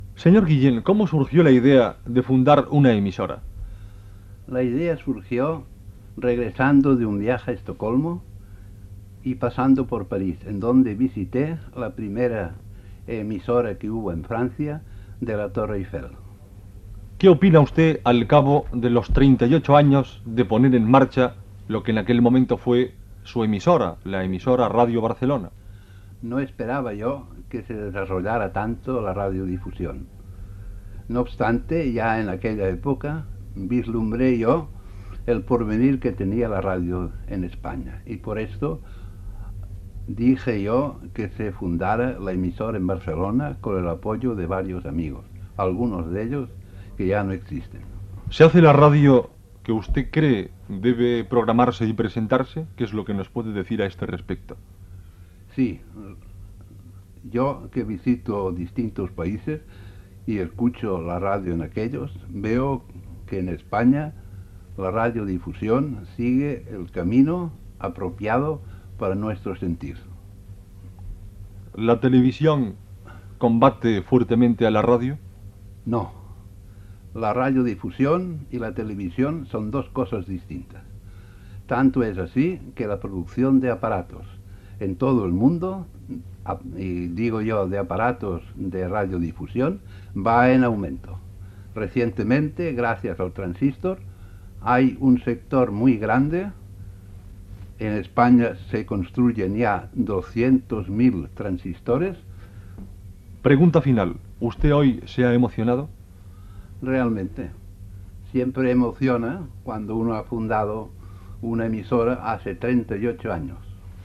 Gènere radiofònic